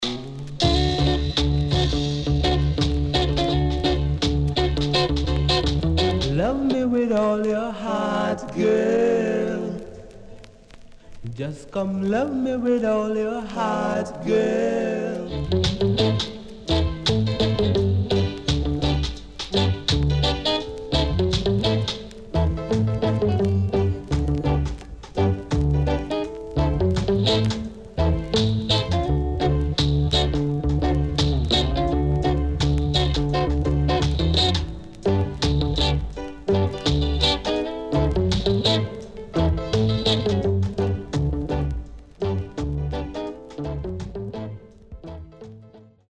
Nice Rocksteady Vocal
SIDE A:全体的にプレス起因？でノイズ入ります。